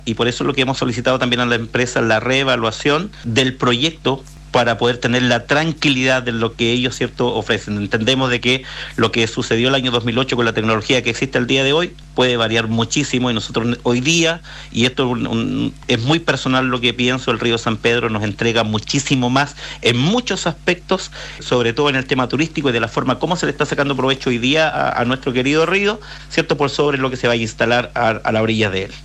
En una entrevista exclusiva con Radio Bio Bio, el alcalde de Los Lagos, Víctor Fritz, reiteró su rechazo a la instalación de una piscicultura en el río San Pedro y confirmó que el Concejo Municipal de forma unánime se opone a esta iniciativa.